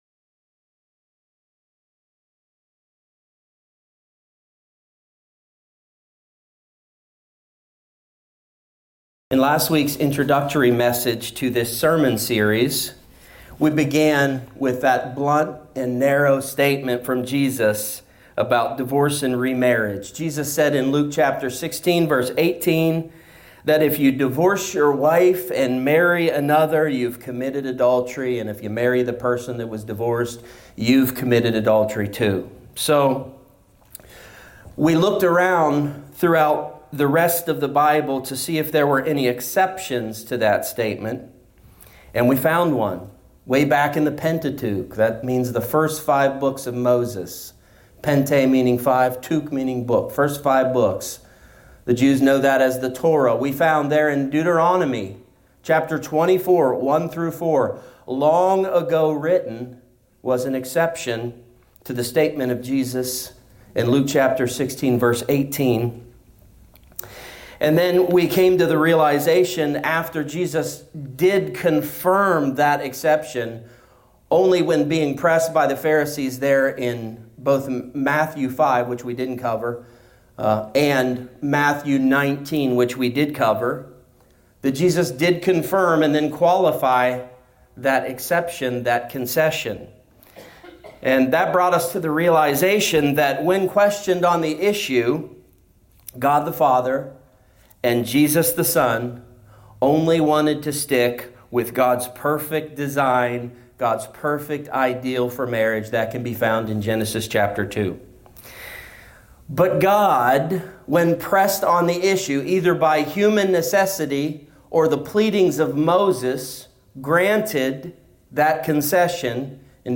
A topical teaching on the subjects of singleness, marriage, divorce, and remarriage.